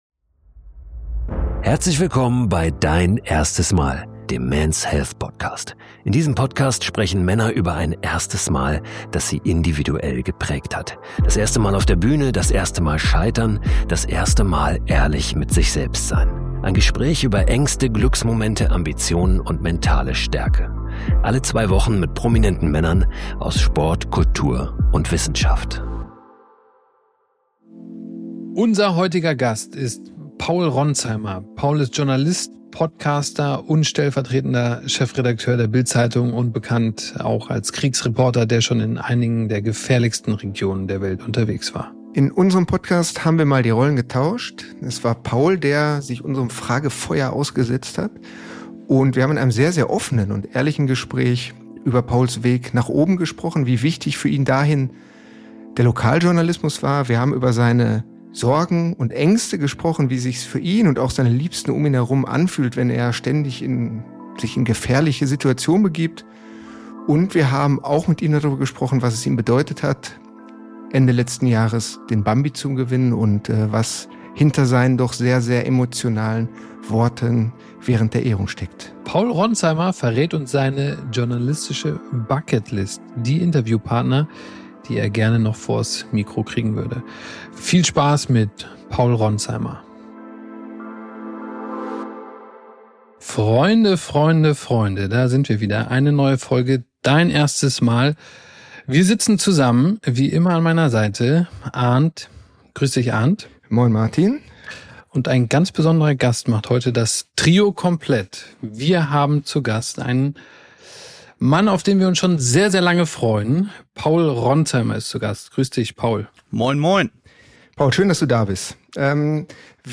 Wir haben die Rollen getauscht, bei uns hat Paul ausnahmsweise mal nicht die Fragen gestellt, sondern sie beantwortet.